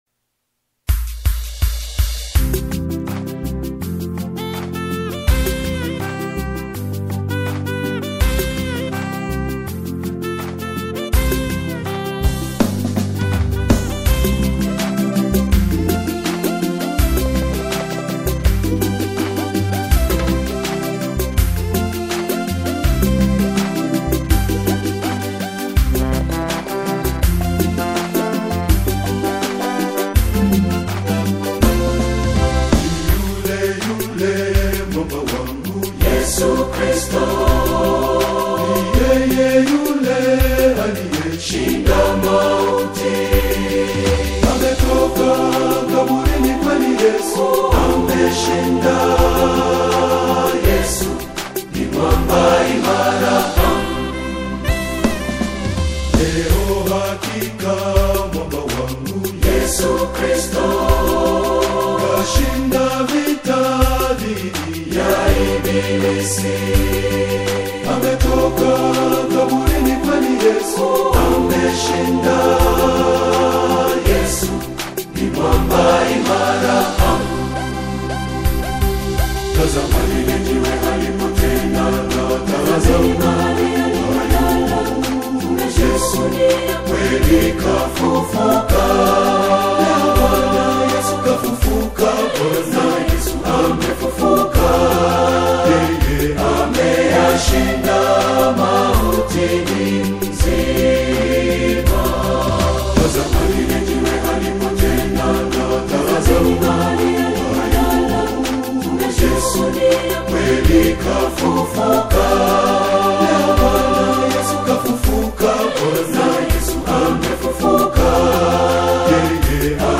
a majestic and deeply theological single